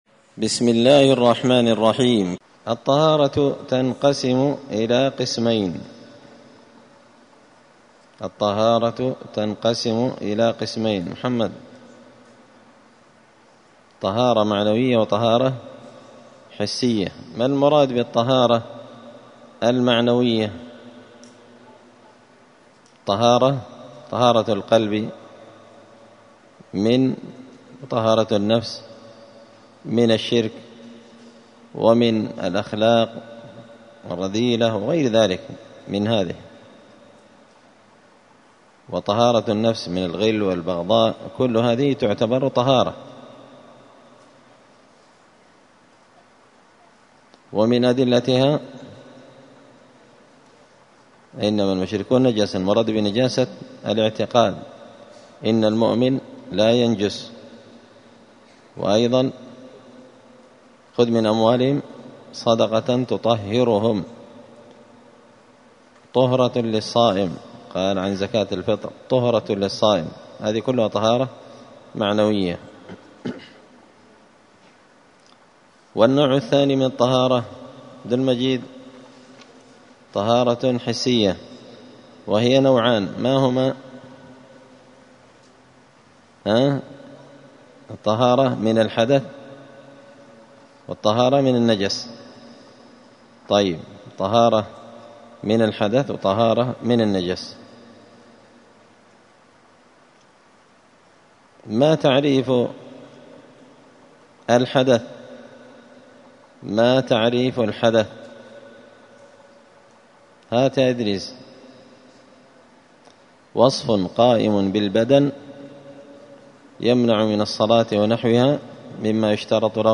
دار الحديث السلفية بمسجد الفرقان بقشن المهرة اليمن
*الدرس الثاني (2) {باب المياه…}*